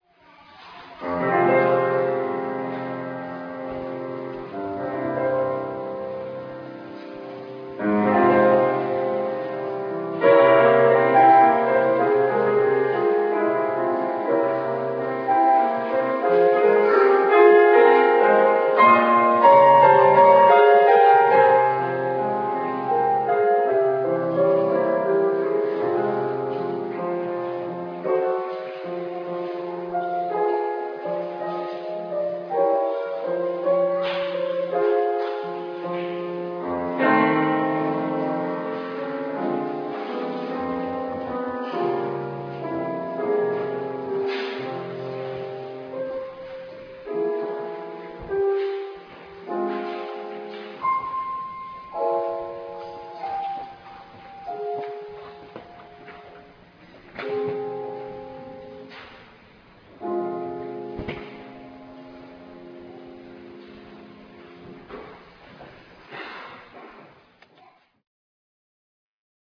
小さな音楽会
会場：あいれふホール